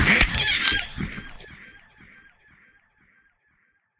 sounds / mob / horse / skeleton / hit4.ogg
hit4.ogg